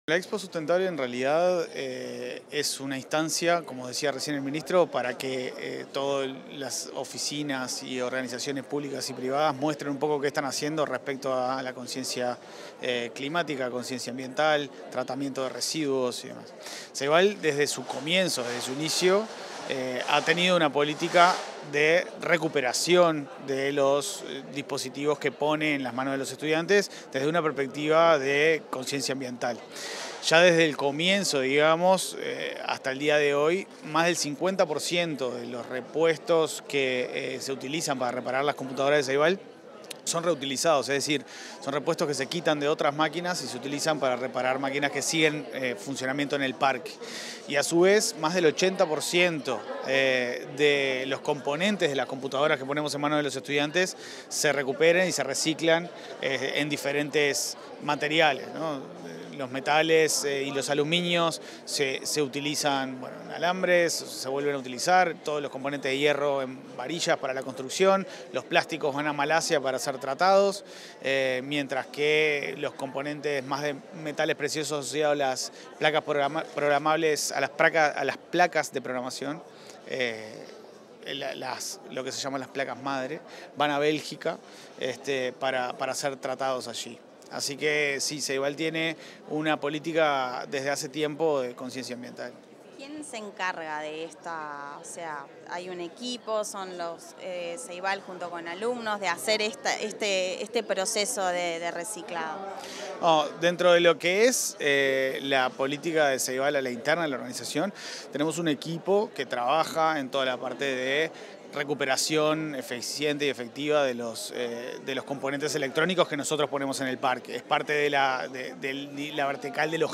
Entrevista al presidente de Ceibal, Leandro Folgar